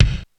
Index of /90_sSampleCDs/300 Drum Machines/Korg DSS-1/Drums02/01
Kick 02.wav